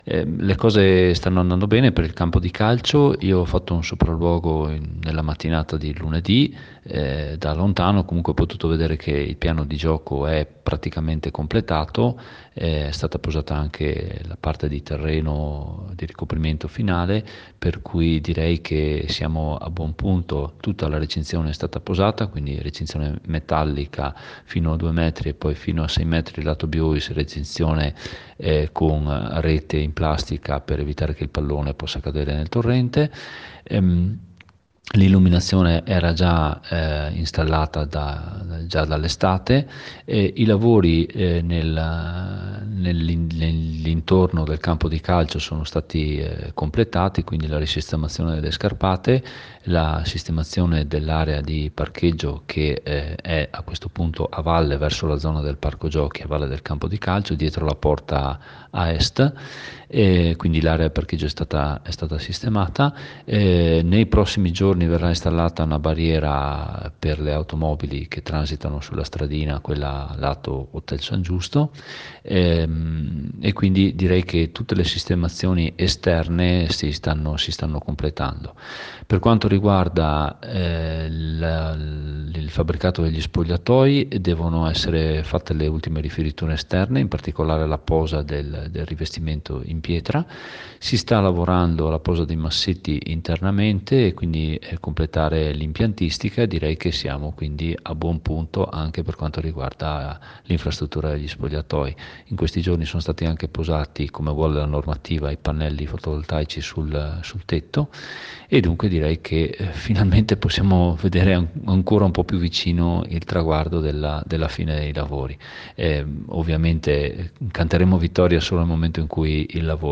FALCADE Sono a buon punto i lavori del campo sportivo di Falcade. Un lavoro che renderà lustro alla Valle del Biois come spiega il sindaco di Falcade Michele Costa